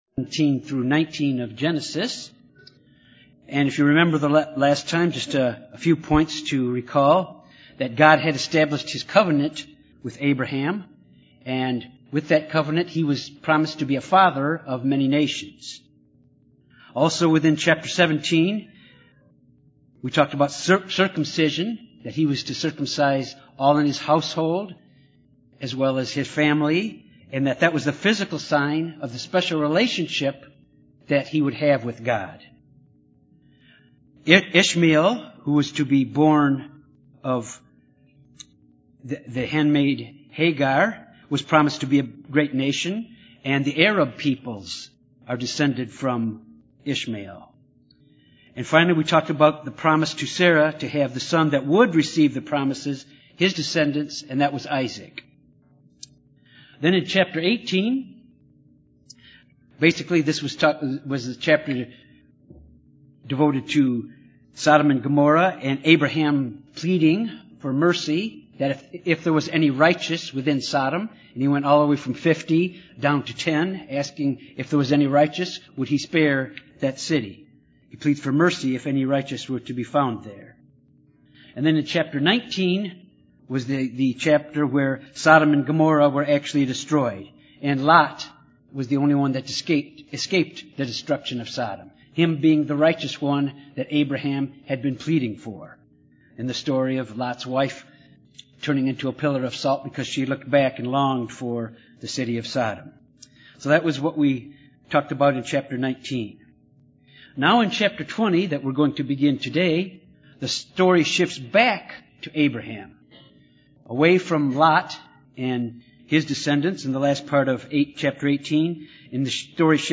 This Bible study focuses on Genesis 20-21.
Given in Little Rock, AR